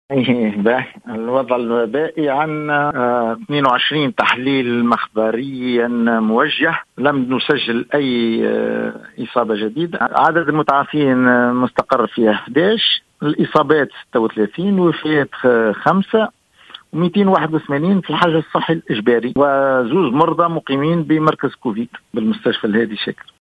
أكد المدير الجهوي للصحة بصفاقس علي العيادي، في تصريح اليوم لـ"الجوهرة أف أم" عدم تسجيل أي إصابات جديدة بفيروس "كورونا" من جملة 22 تحليلا مخبريا.